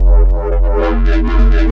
Bass 1 Shots (100).wav